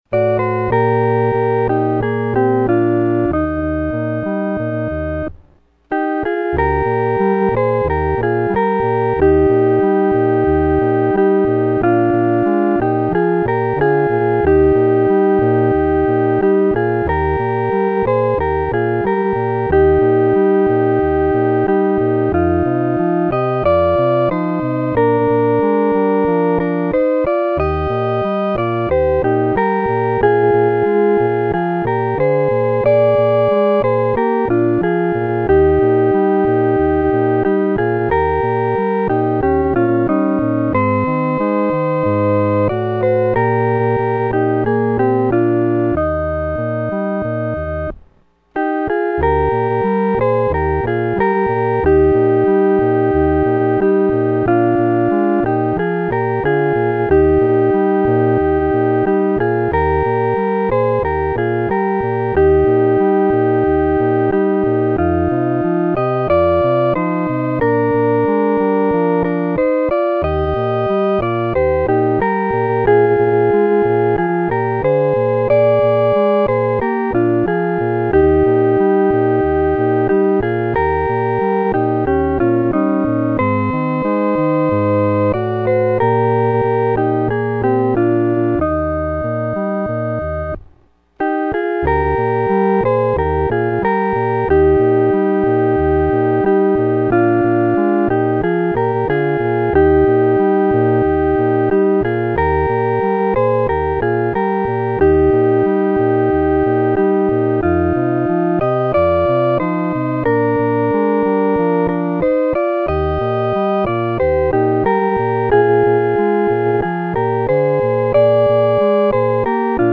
合奏（二声部）